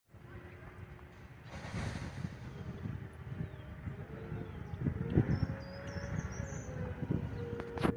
Im Volkspark Friedrichshain
Gartenbaumläufer in Berlin
Gartenbaumlaeufer-1.mp3